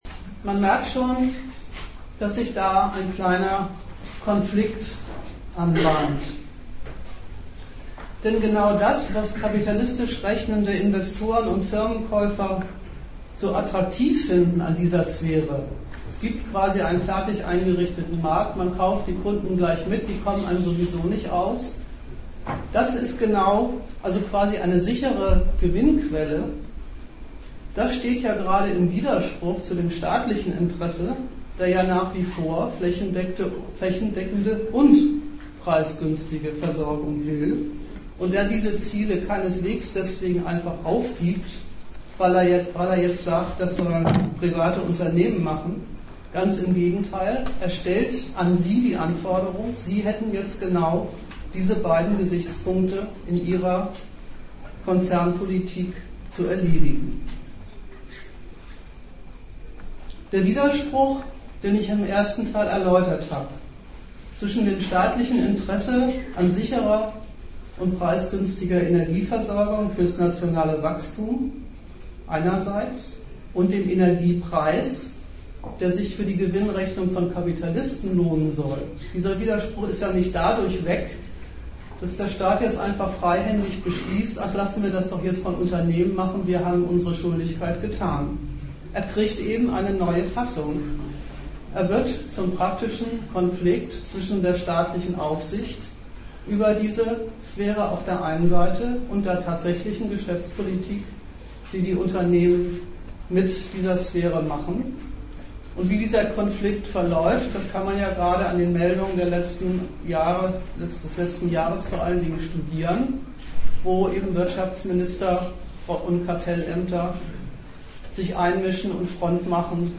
Gliederung des Vortrags:Teile 1+2:
Dozent Gastreferenten der Zeitschrift GegenStandpunkt